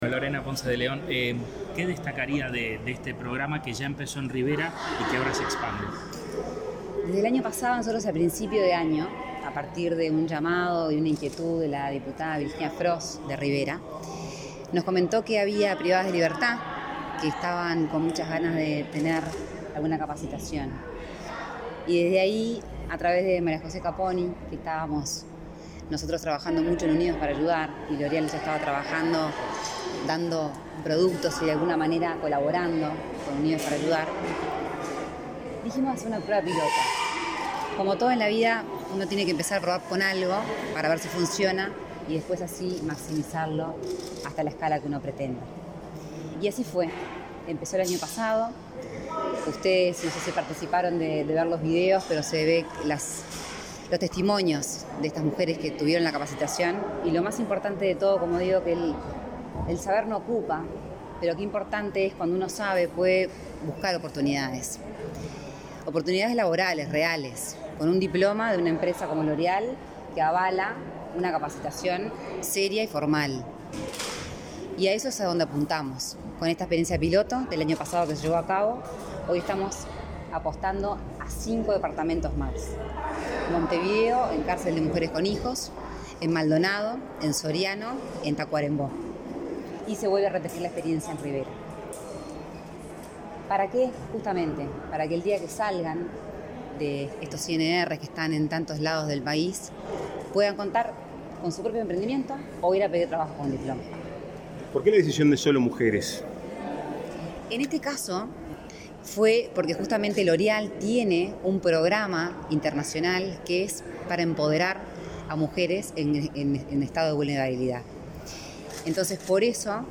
Declaraciones a la prensa de la impulsora del programa Sembrando, Lorena Ponce de León
Declaraciones a la prensa de la impulsora del programa Sembrando, Lorena Ponce de León 21/04/2022 Compartir Facebook X Copiar enlace WhatsApp LinkedIn Con la asistencia del presidente de la República, Luis Lacalle Pou, Sembrando lanzó una iniciativa de formación laboral para mujeres privadas de libertad, este 21 de abril. Tras el evento, la impulsora del programa, Lorena Ponce de León, efectuó declaraciones a la prensa.